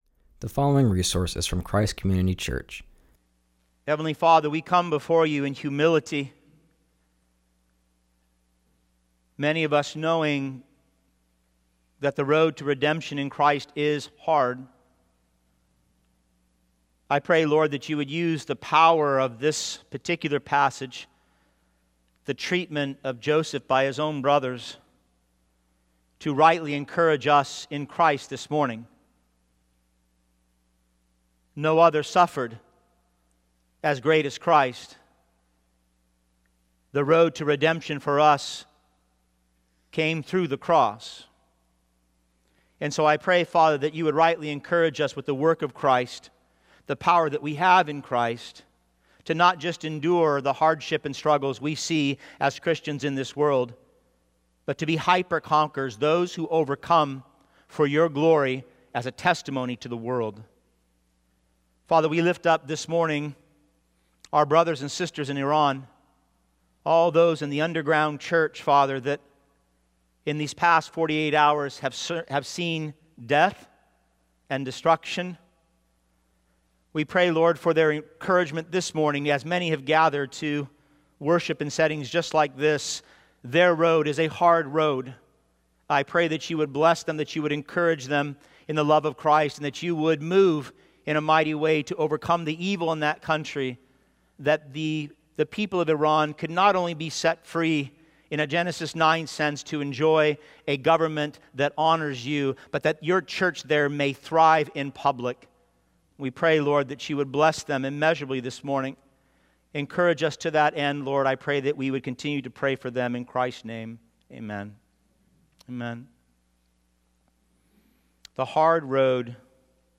continues our series and preaches from Genesis 37:1-11